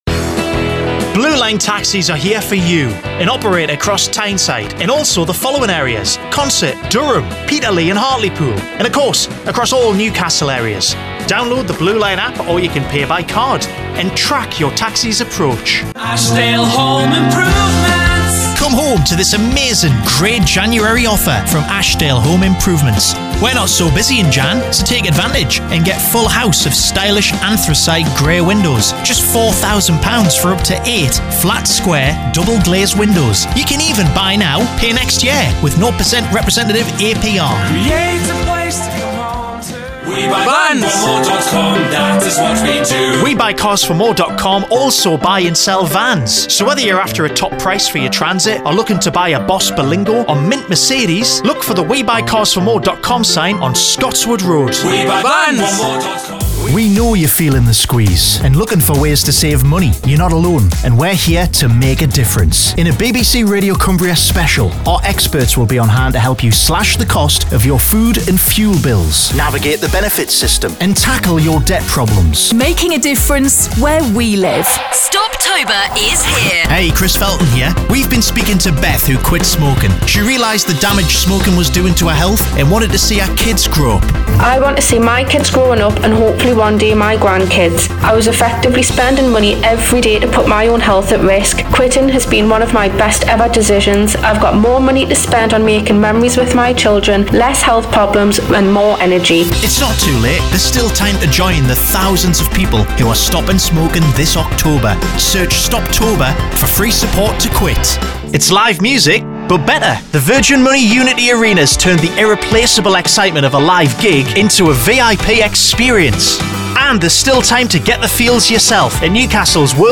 Geordie
Range 30s - 50s
Warm, friendly & engaging North East accent.